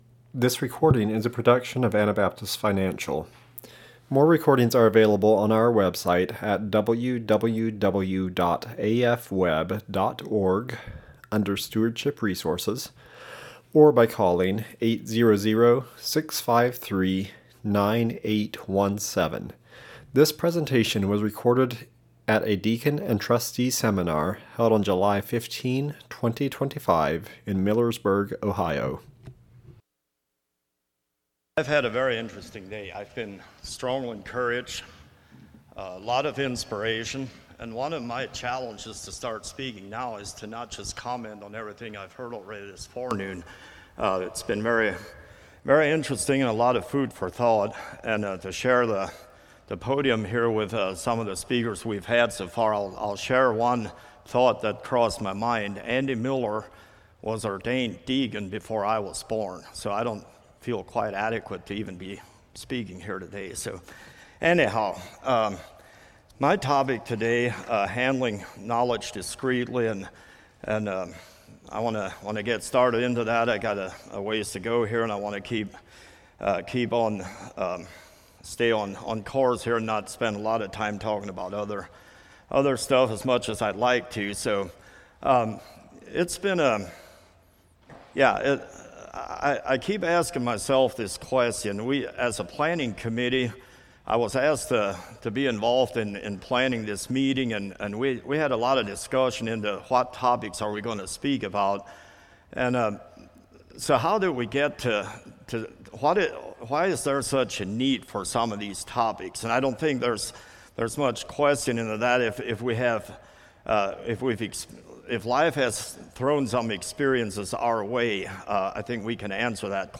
Ohio Deacons Seminar 2025 / Brotherhood Accountability What happens if the church is no longer a safe haven, but instead, becomes a newsroom? Could we do better as churches, ministries and support groups (trustees) to handle knowledge in a more discreet manner?